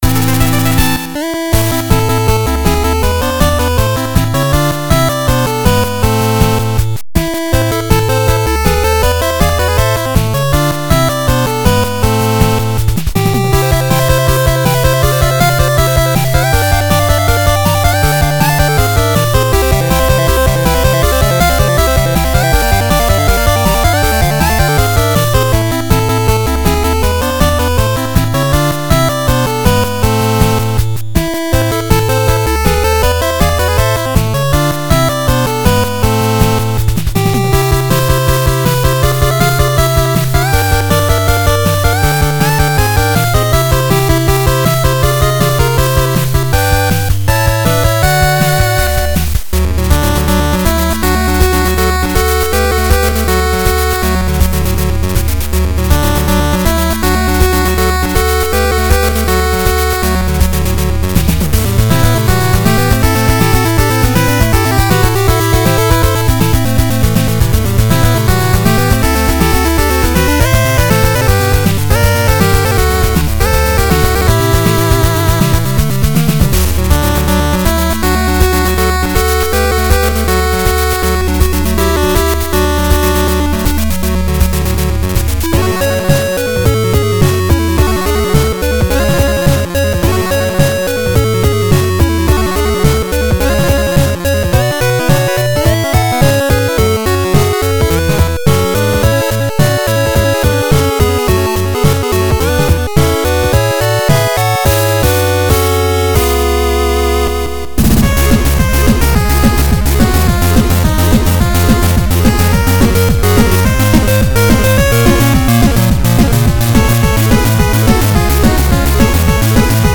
BPM107--12